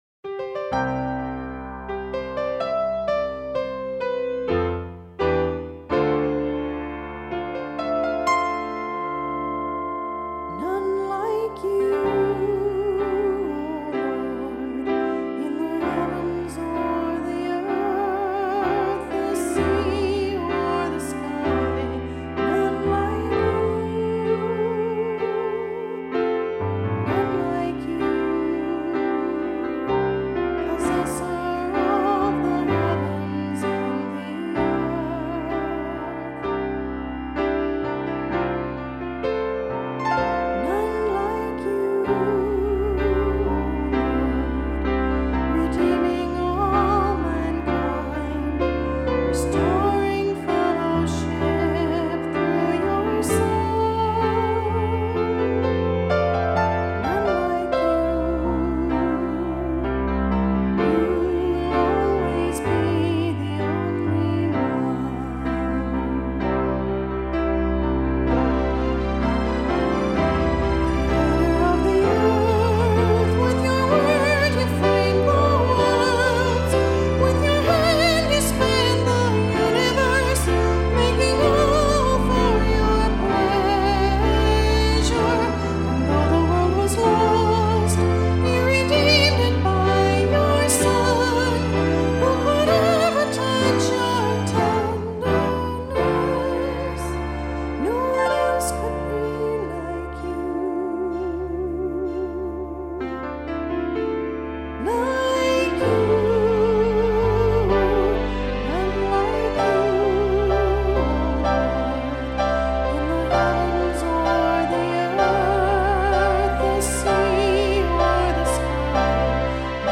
who played the piano brilliantly for this song.